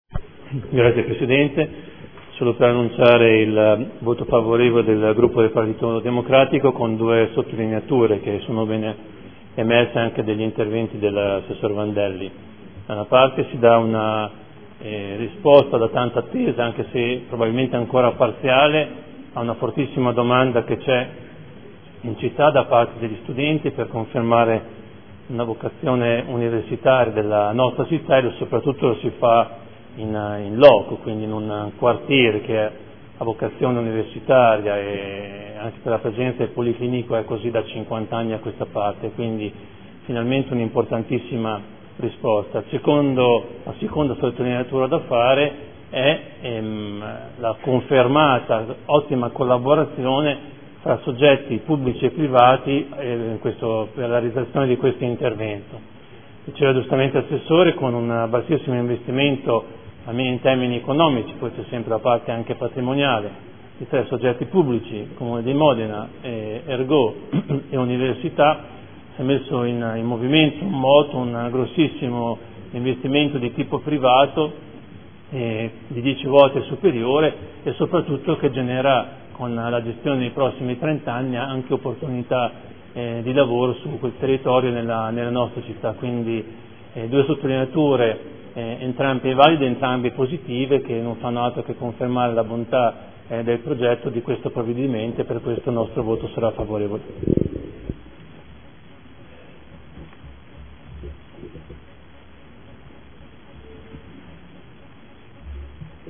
Seduta del 09/03/2015 Proposta di deliberazione: Concessione di lavori pubblici per la costruzione e gestione di alloggi per studenti e personale universitario a canone concordato denominata “Campus Campi” – Promessa di cessione di quote di proprietà superficiaria a UNIMORE – Università degli Studi di Modena e Reggio Emilia. Dichiarazione di voto